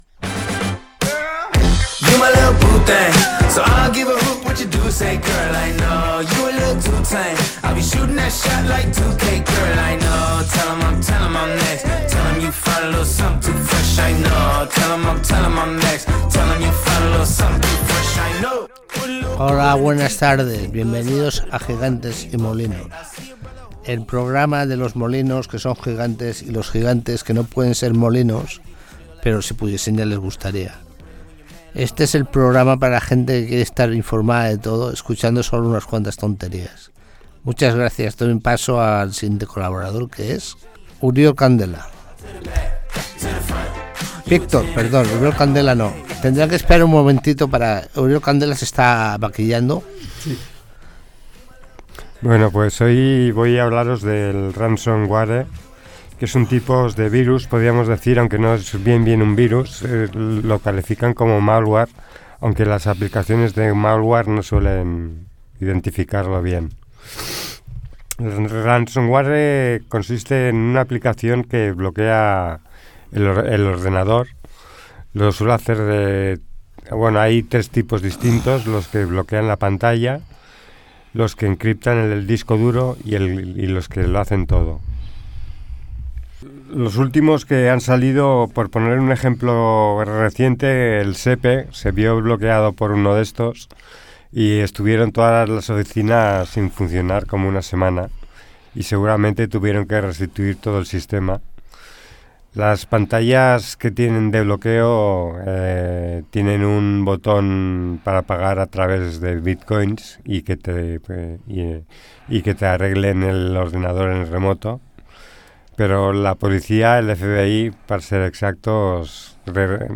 Aquesta nova entrega enregistrada als estudis de Ràdio Fabra també inclou un repàs a l'actualitat esportiva, recomanacions per cinèfils i consells per evitar ser víctimes de 'malware'.